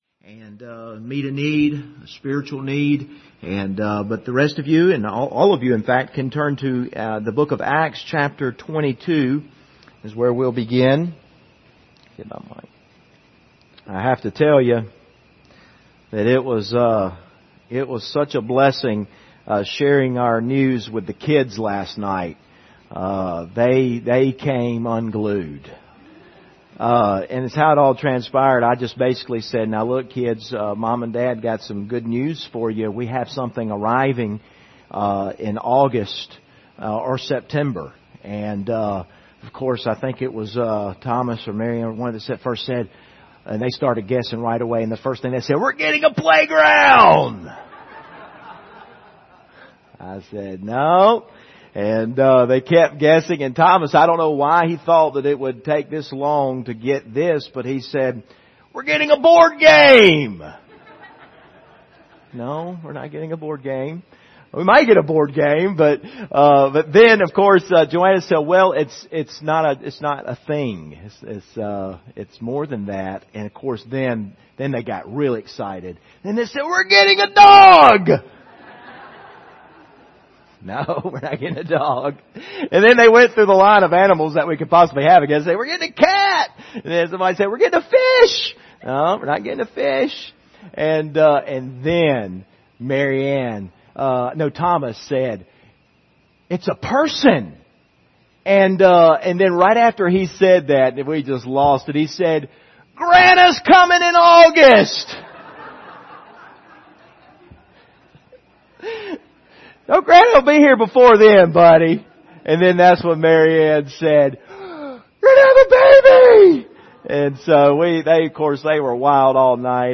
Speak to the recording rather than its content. Passage: Acts 22:24-23:5 Service Type: Sunday Morning